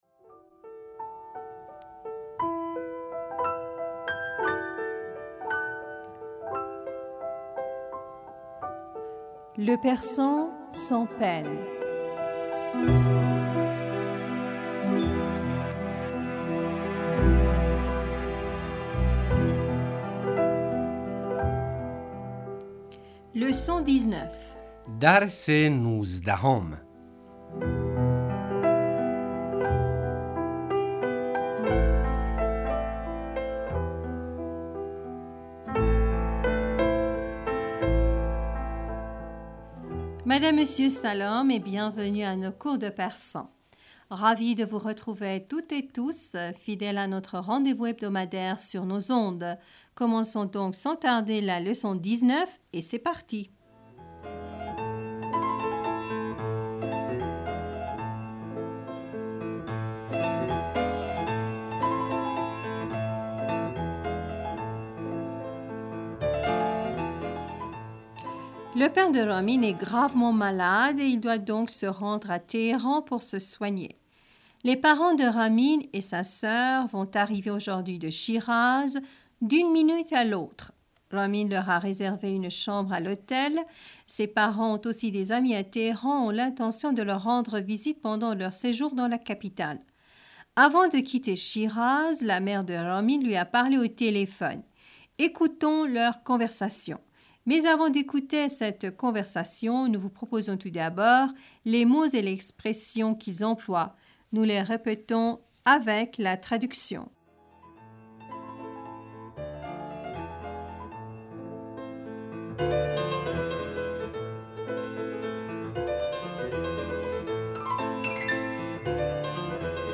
Nous les répétons deux fois, avec la traduction.
Nous répétons chaque phrase avec la traduction.